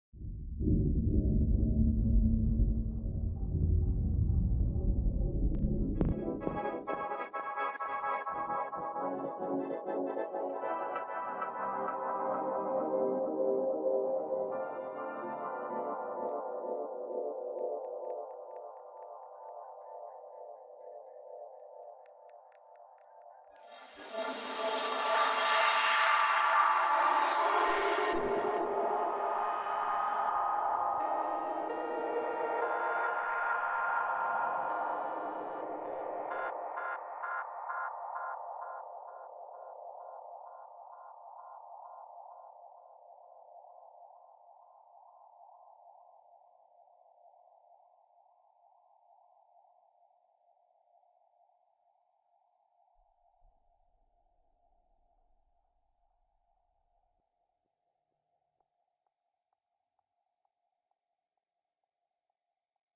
破碎的飞船音画科幻恐怖片
描述：这是在一个单一的合成器补丁（reaktor）中制作的，没有各种合成器的分层。
标签： 130 bpm Cinematic Loops Soundscapes Loops 4.97 MB wav Key : Unknown FL Studio
声道立体声